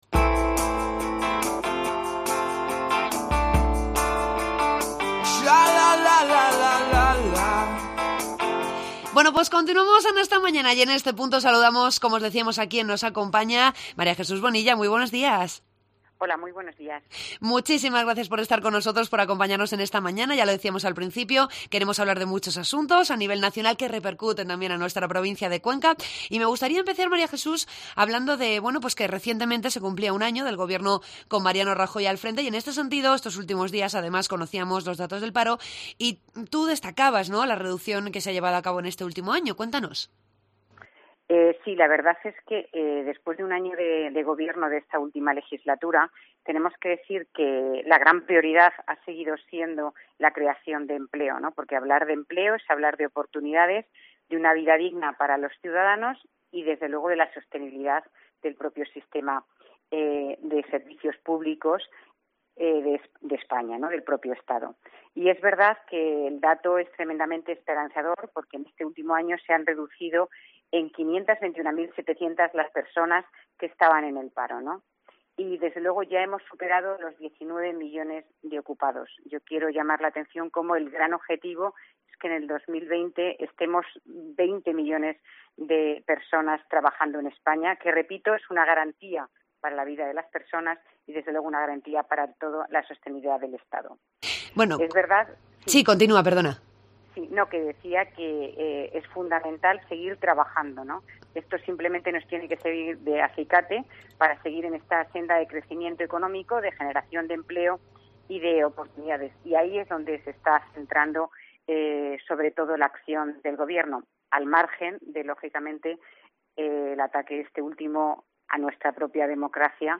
Entrevista a la diputada nacional del Partido Popular por la provincia de Cuenca, María Jesús Bonilla.